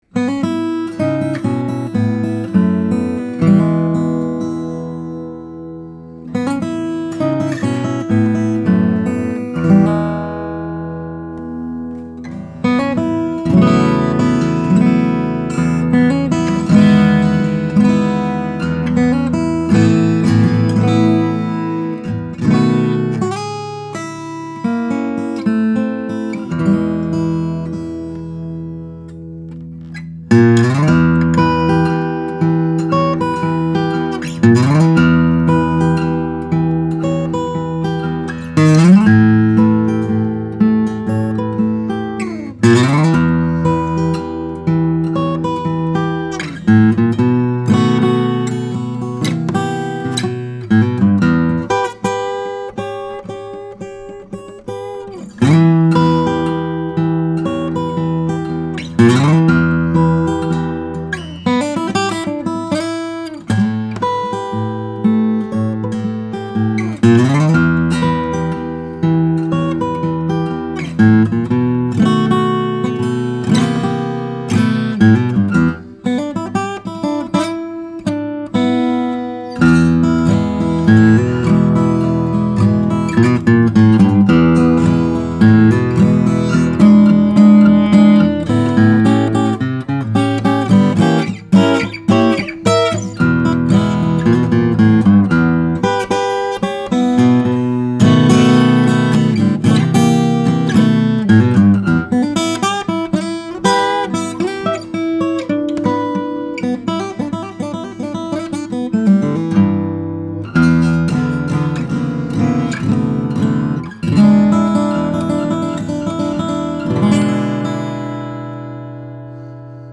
Flamed Koa back and sides are paired with an Engelmann Spruce top for a crisp bass and midrange response, firecracker trebles, and an energetically-charged voice from fret 1…
Collings_OM2H_2013.mp3